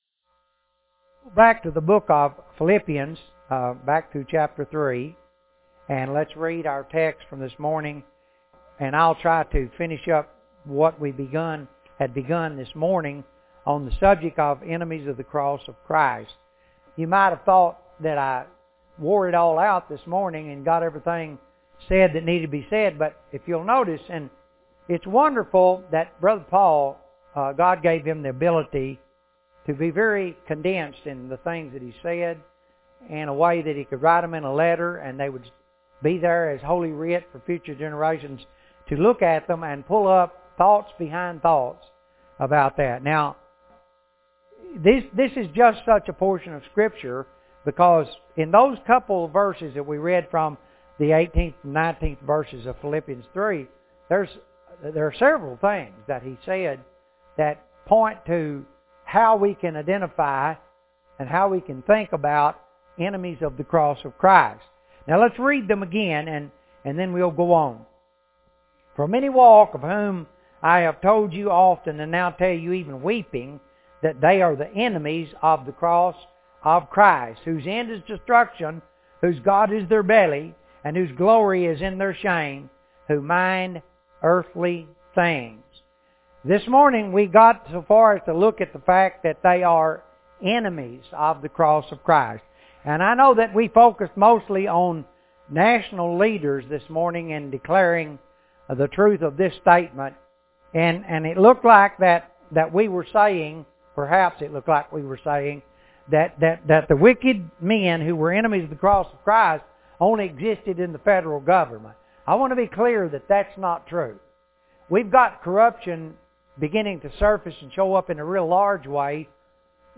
Clicking on an item will open an audio sermon on the subject.